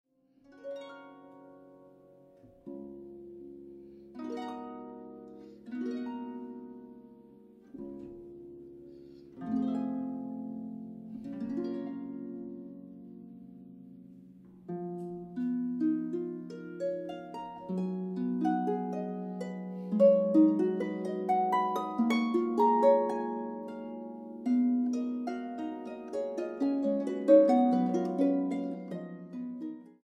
Boonkker Audio Tacubaya, Ciudad de México.